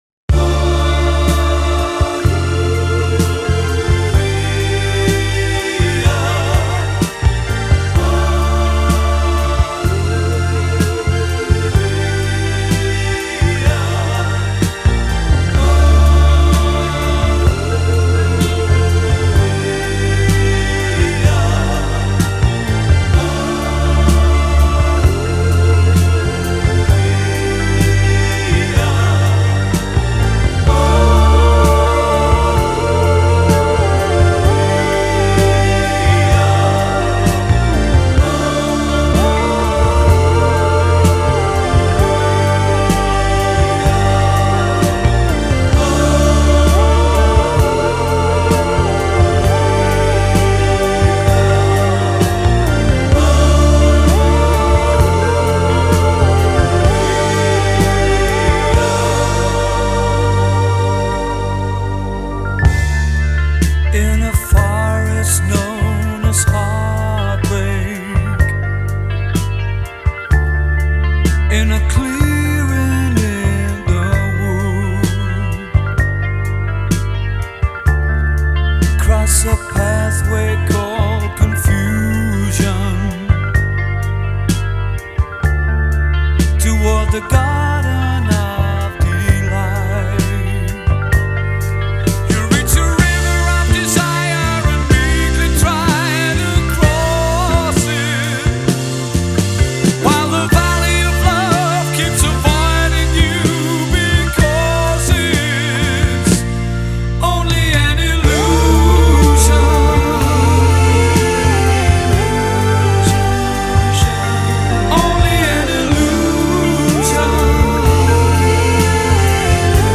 Жанр: Хард-рок, AOR
Originally recorded as one track